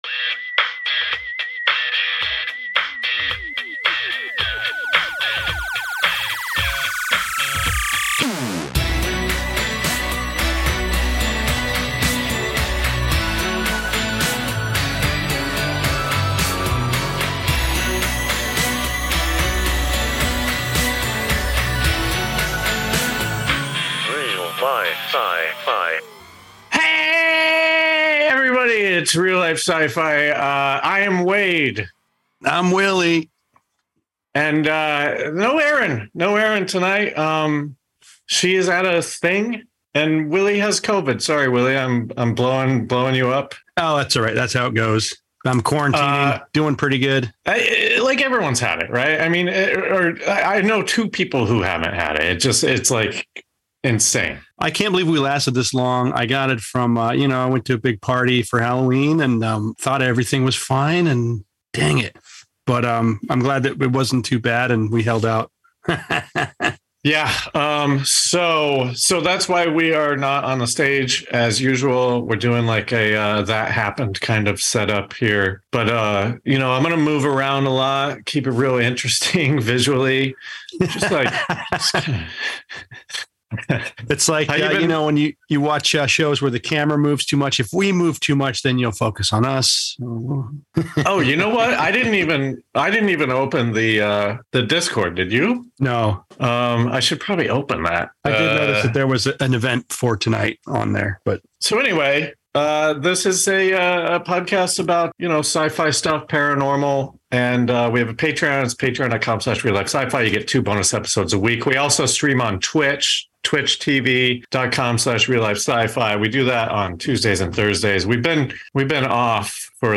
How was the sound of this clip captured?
we ended up zooming